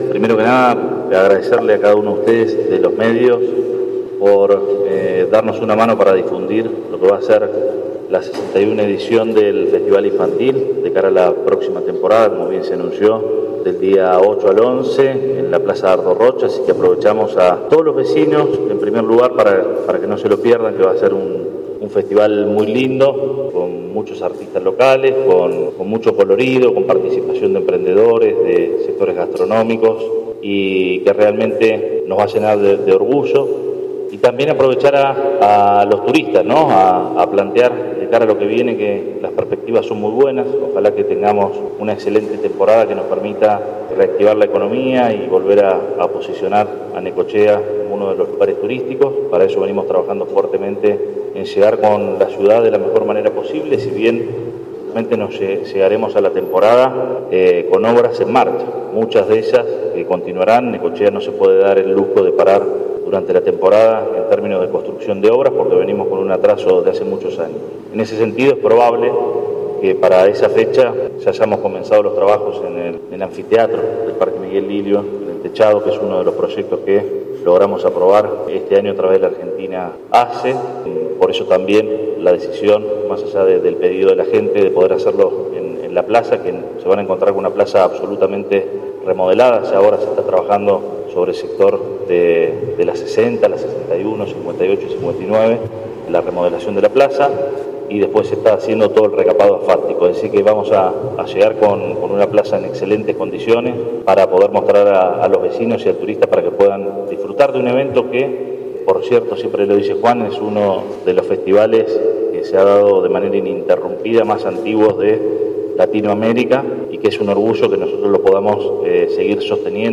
En una conferencia de prensa realizada en el Salón de Actos de la Municipalidad de Necochea, el intendente Arturo Rojas, acompañado por parte de su gabinete, presentaron en sociedad la edición 61º del Festival Infantil de Necochea, que tendrá desarrollo entre el sábado 8 y el martes 11 de enero, con epicentro en la Plaza Dardo Rocha.
04-11-AUDIO-Arturo-Rojas-Festival-Infantil.mp3